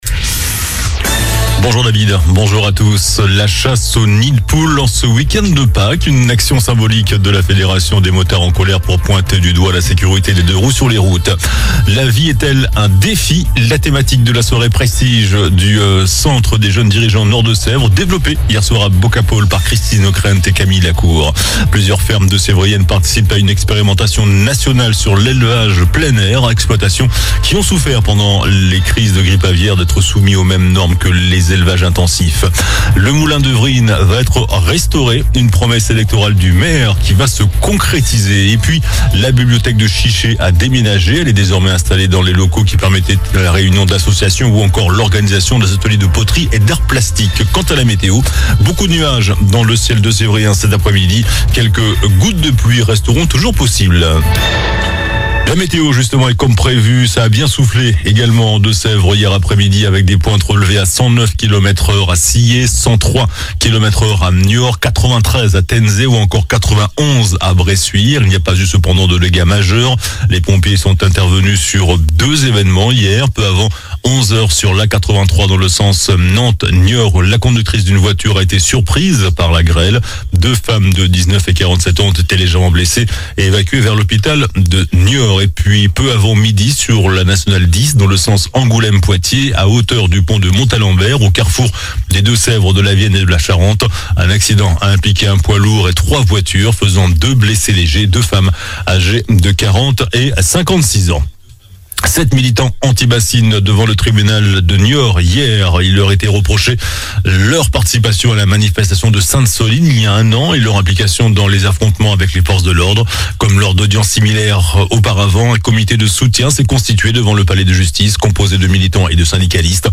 JOURNAL DU VENDREDI 29 MARS ( MIDI )